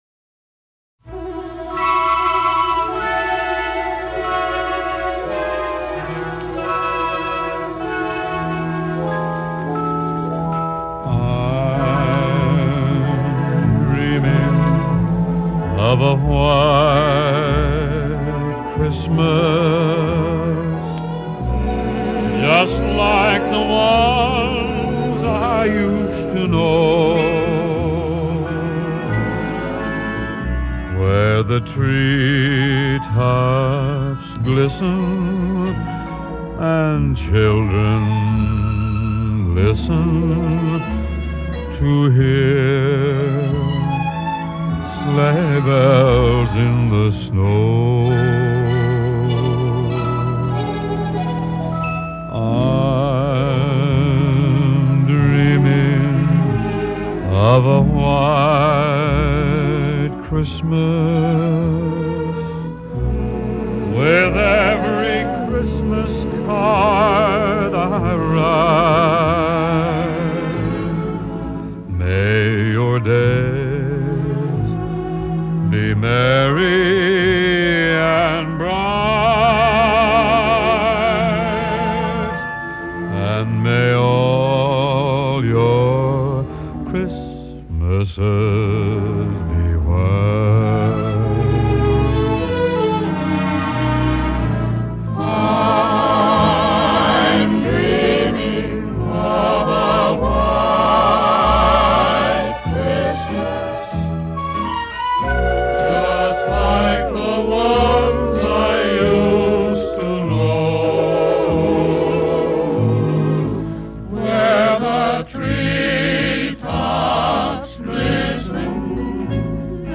Genre: Pop, Rock, Jazz, Christmas